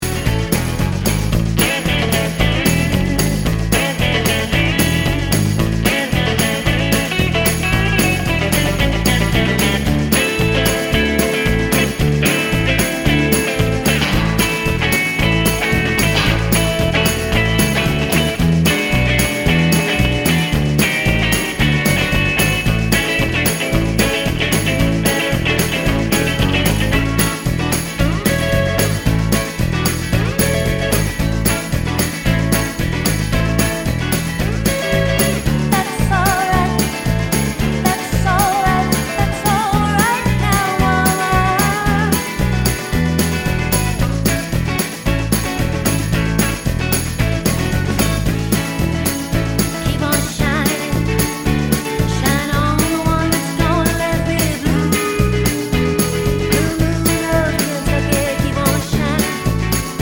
no Backing Vocals Medleys 5:10 Buy £1.50